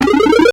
vinesprout.wav